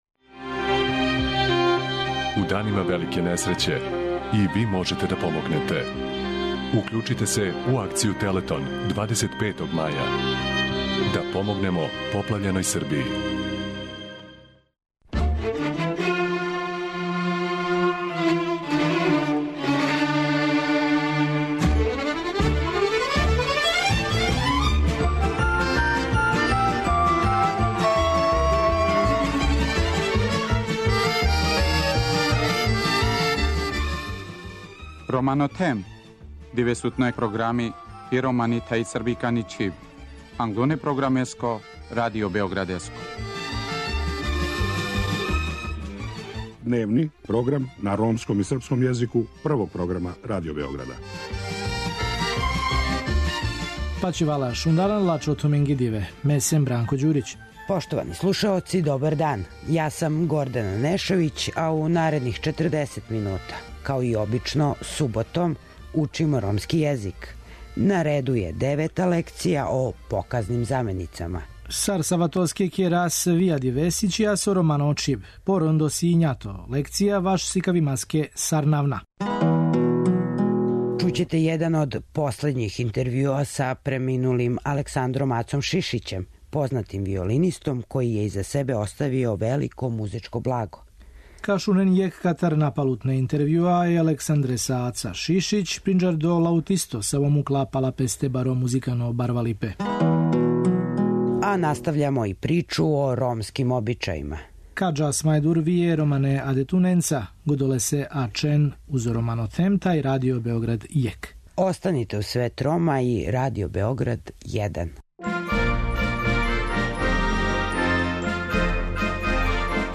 интервјуа